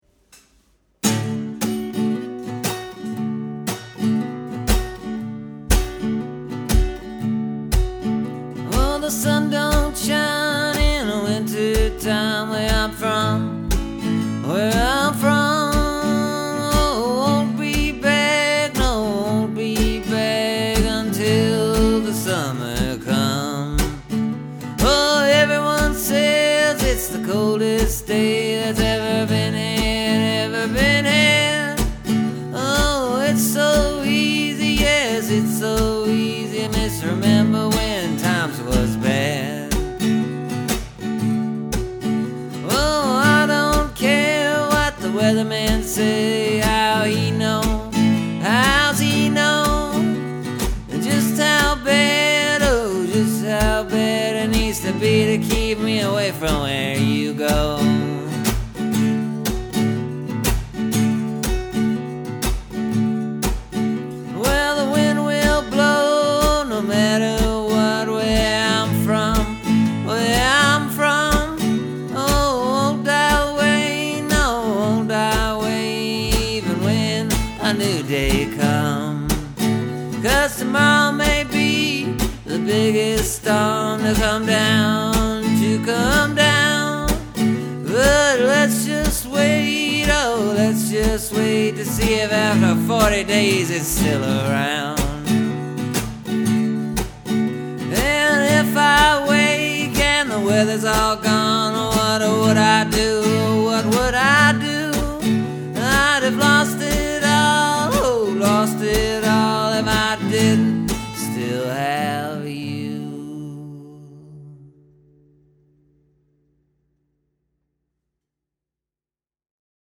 Anyway, I was up-the-neck, so I just was kinda fooling around.